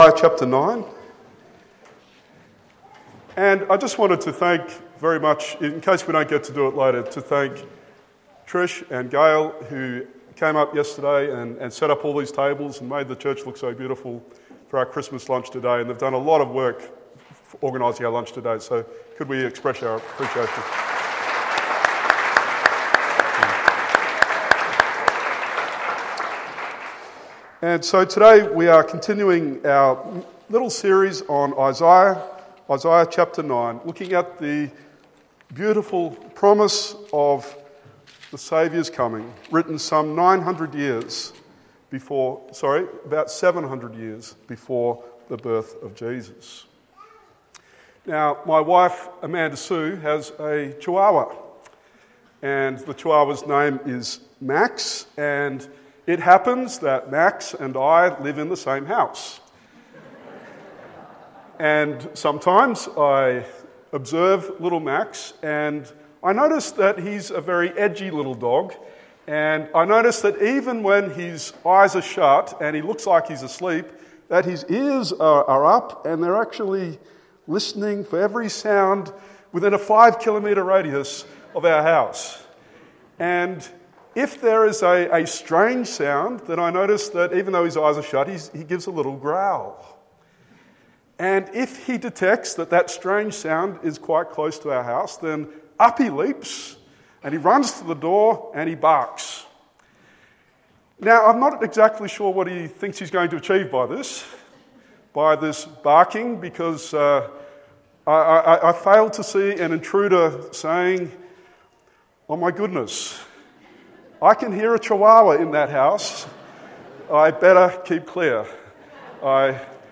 Text: Isaiah 8:6-9:1 Sermon